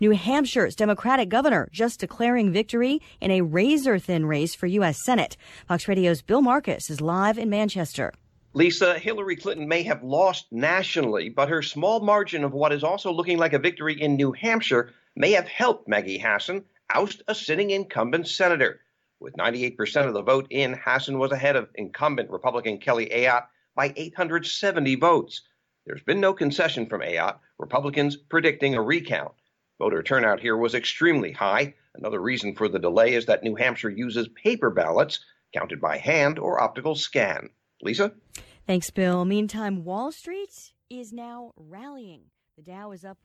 (MANCHESTER, NH) NOV 9, NOON, LIVE: